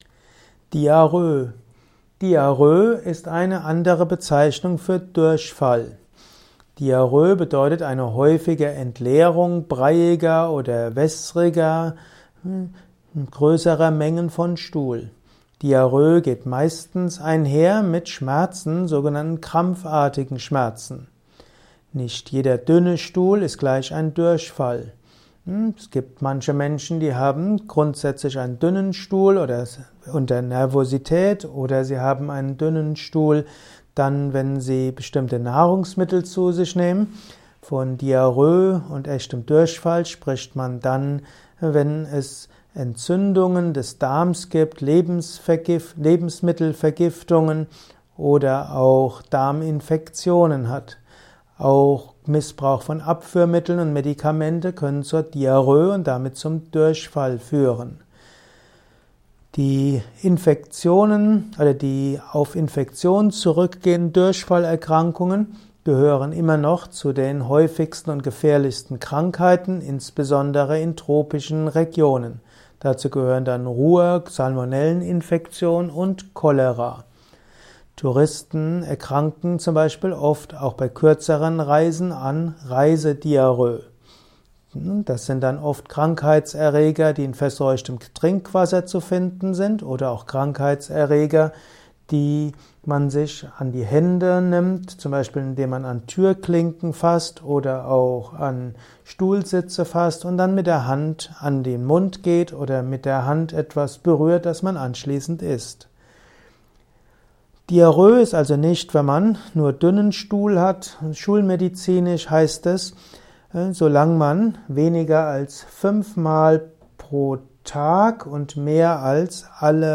Ein Kurzvortrag über den Diarrhö